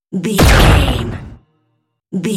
Dramatic hit thunder laser shot
Sound Effects
Atonal
heavy
intense
dark
aggressive